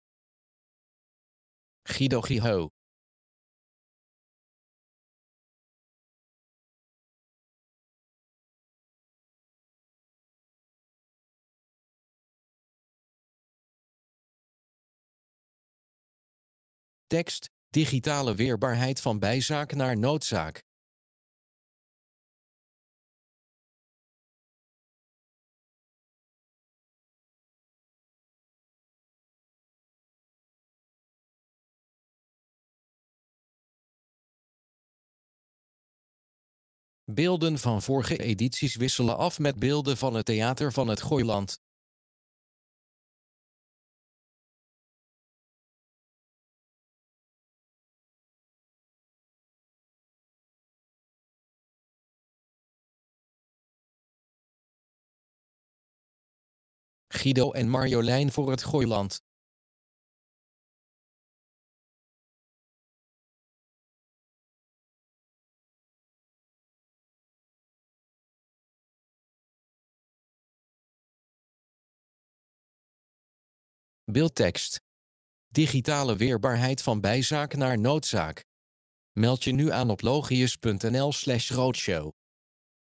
Een vrouw kijkt rechtstreeks in de camera en spreekt de kijkers toe.